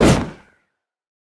wind_burst2.ogg